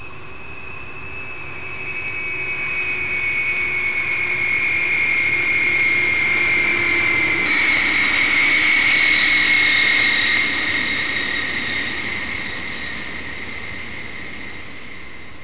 جلوه های صوتی
دانلود آهنگ طیاره 46 از افکت صوتی حمل و نقل